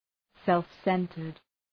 Shkrimi fonetik {,self’sentərd}